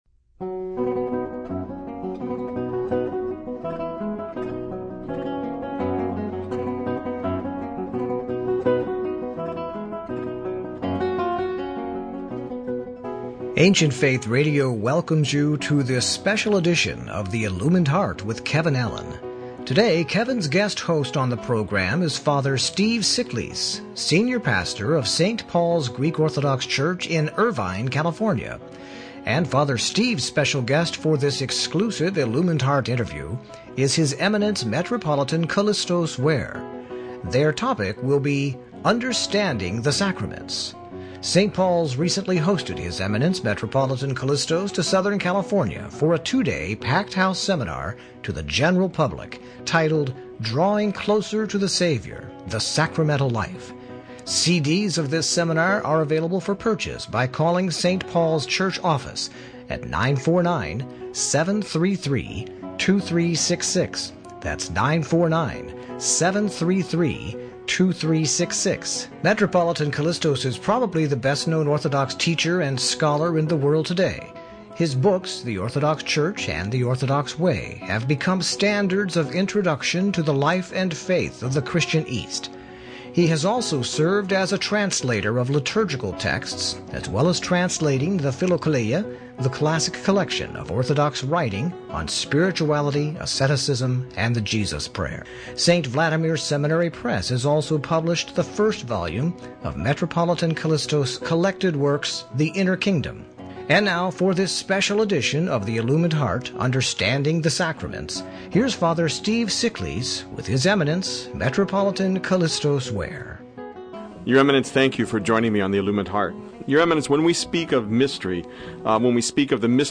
Metropolitan Kallistos Ware on the Sacramental Life Understanding the Sacraments An Interview with Metropolitan KALLISTOS Ware